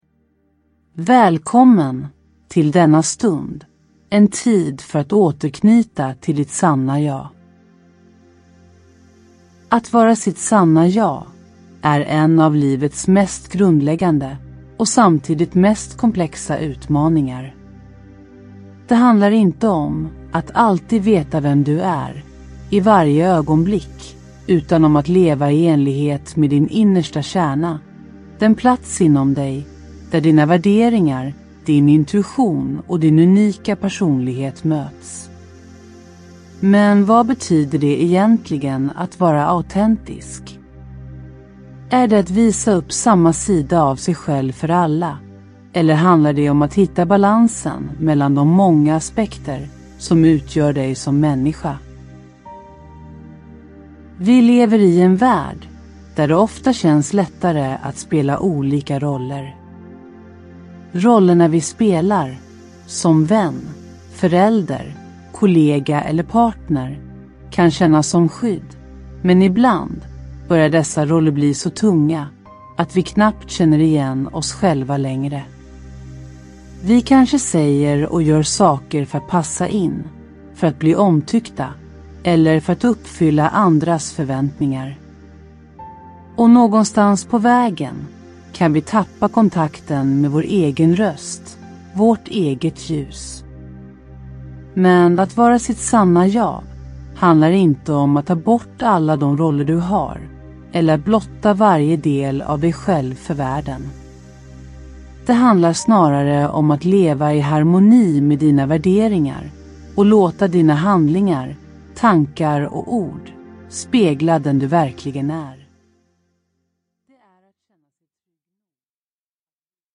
Ljudbok
Med en kombination av guidad avslappning, stärkande visualiseringar och kraftfulla affirmationer hjälper denna meditation dig att: